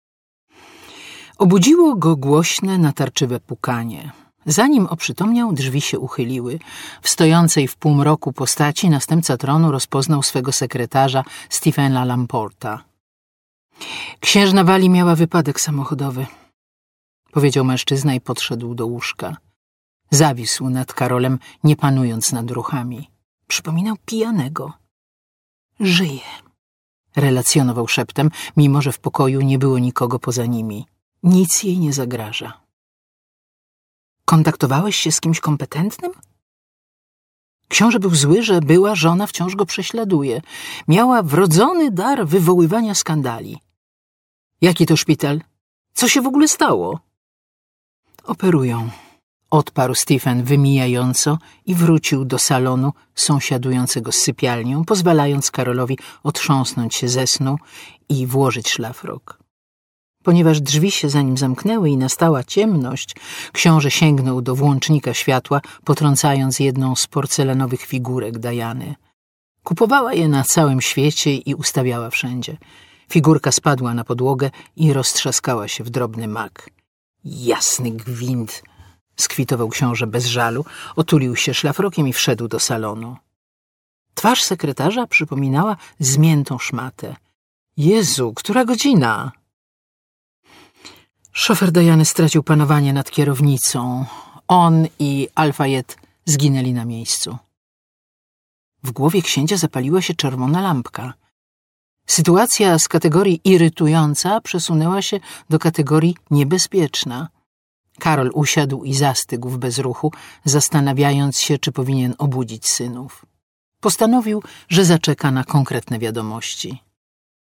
Opowieści z angielskiego dworu. Camilla - Magdalena Niedźwiedzka - audiobook